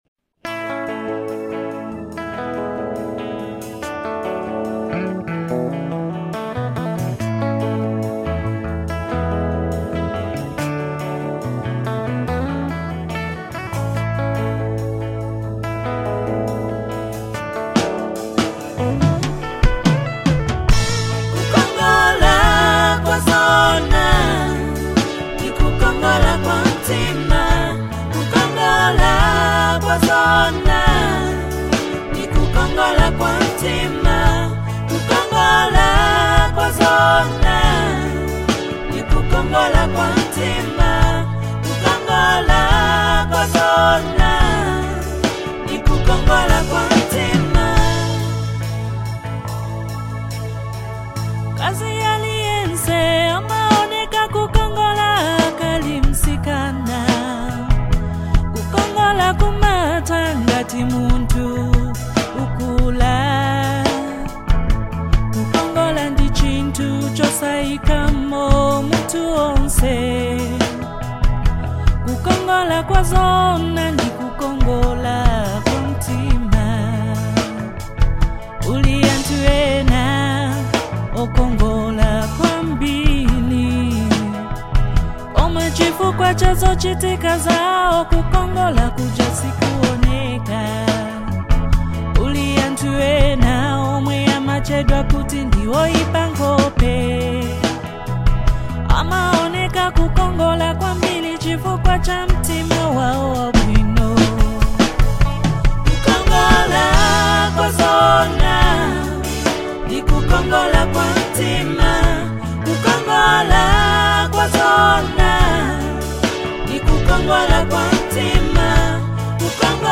heartfelt delivery gives the song a deeply emotional feel